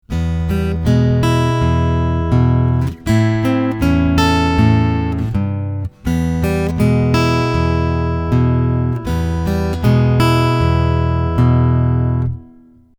Easy Acoustic Blues Pattern – Combo
With the bass and melody lines worked out separately on your fretboard, you are now ready to combine these two ideas to form the final easy acoustic blues pattern on your guitar.
easy-blues-first-4-bars.mp3